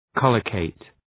Προφορά
{‘kɒlə,keıt}